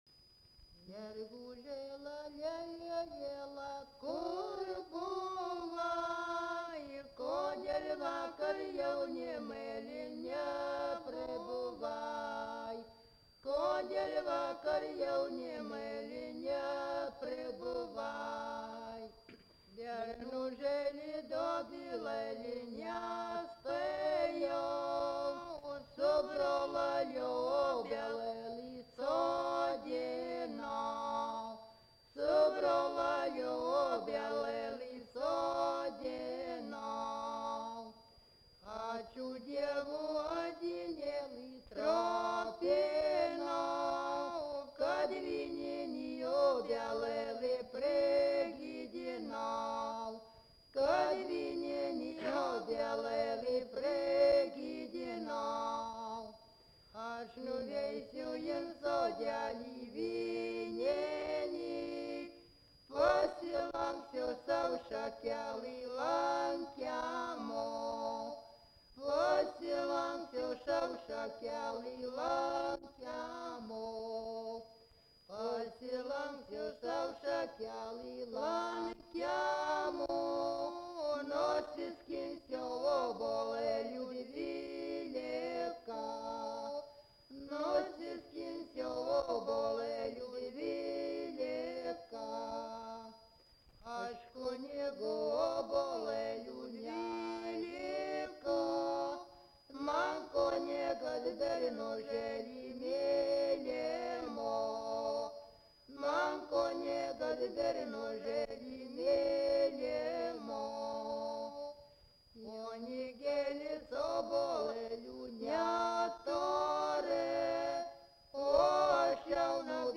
Subject daina
Erdvinė aprėptis Viečiūnai
Atlikimo pubūdis vokalinis